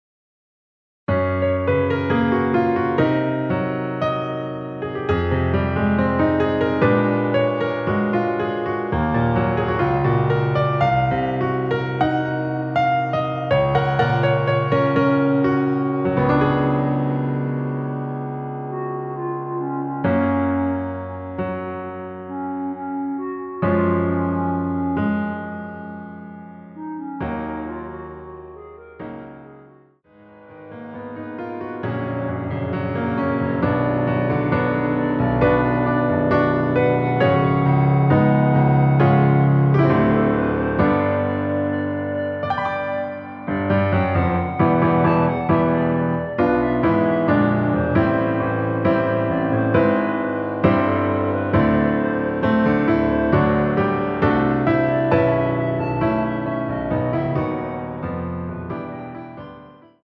원키에서(-2)내린 멜로디 포함된 MR입니다.
F#
앞부분30초, 뒷부분30초씩 편집해서 올려 드리고 있습니다.
중간에 음이 끈어지고 다시 나오는 이유는